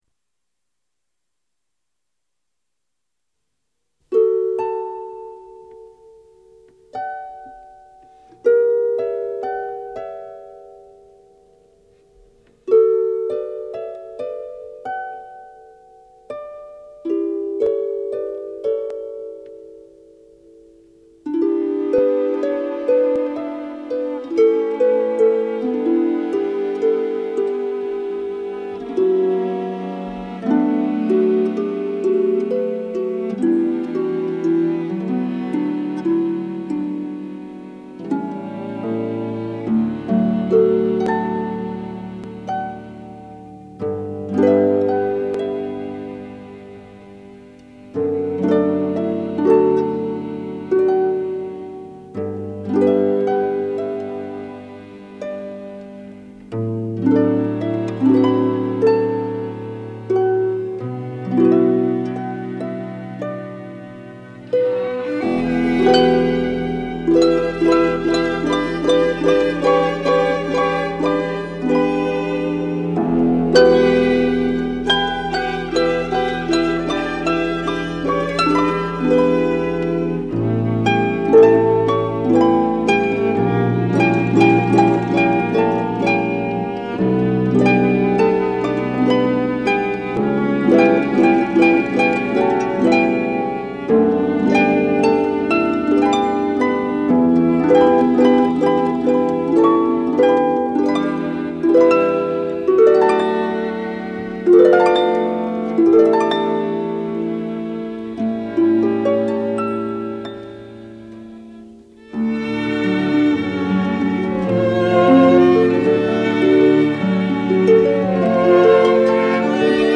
德彪西的《月光曲》 这是德彪西那独特的个性逐渐进入成熟时期的过渡性作品。 月光是《贝加摩组曲》的第三曲，很有表情的行板，9/8拍子。这是德彪西的钢琴作品中最为大众化的一首，美丽的旋律暗示了对月光的印象 委婉的旋律，缓缓起伏，轻轻波动，描绘了月夜特有的诗情画意。
随后，流动的琵音，如同月光荡漾，流畅而舒展。
甚弱和弦的反复更加深了这一印象 接着速度轻快的琶音描写了月光闪烁的皎洁色彩，仿佛置身于晴朗而幽静的深夜氛围之中